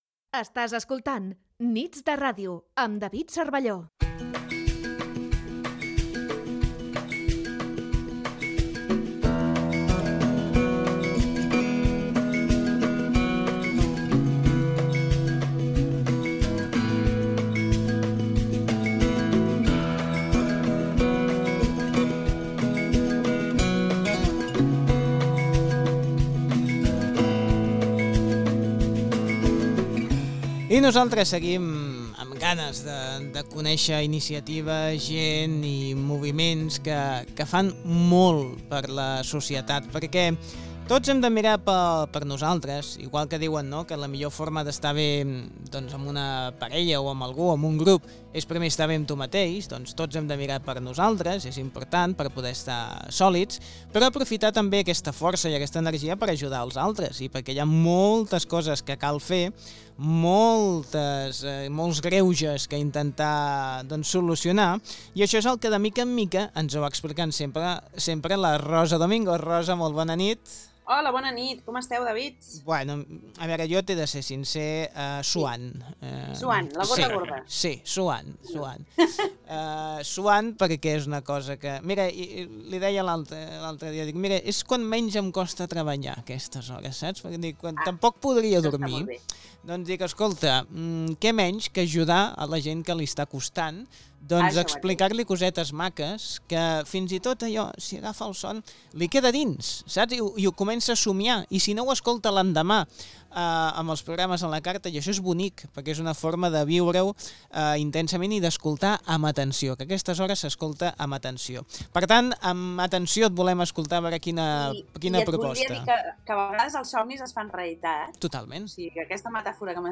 Onda Cero. Nits de Ràdio. 12 de julio de 2022. Escuchar entrevista (en catalán).